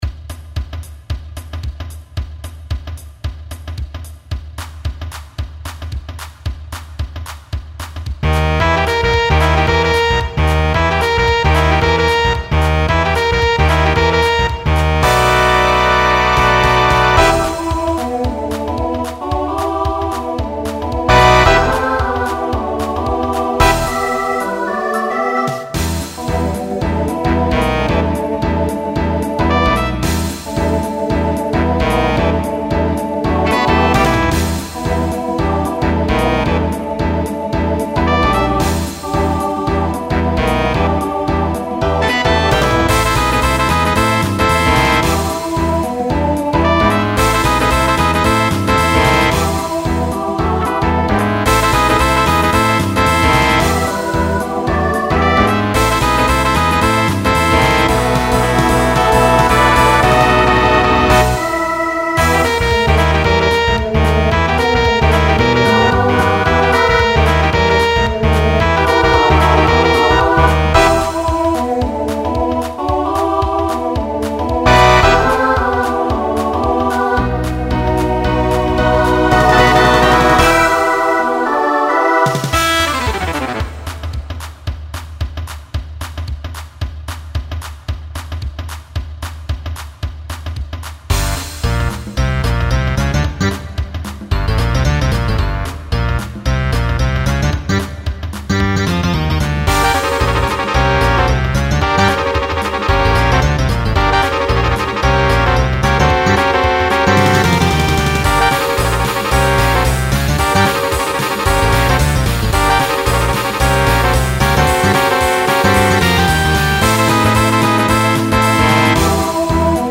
Genre Swing/Jazz Instrumental combo
Voicing SAB